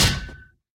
mob / zombie / metal1.ogg
metal1.ogg